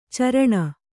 ♪ caraṇa